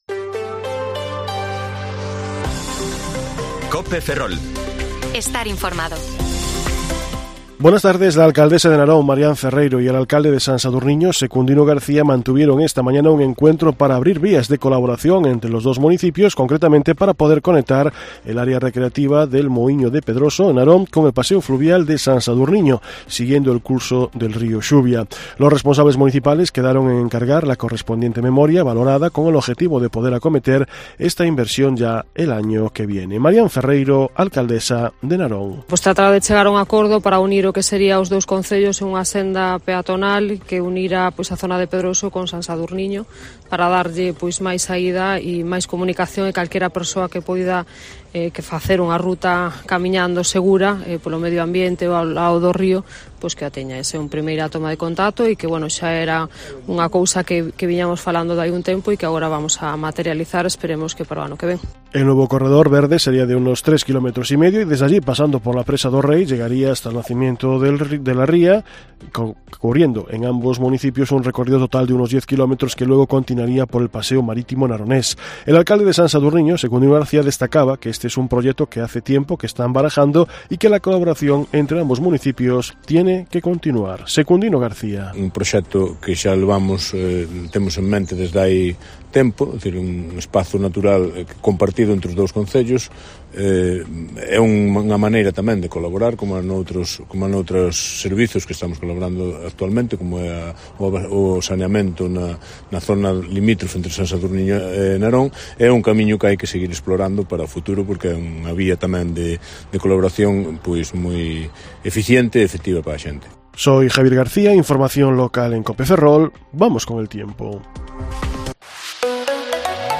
Informativo Mediodía COPE Ferrol 13/12/2022 (De 14,20 a 14,30 horas)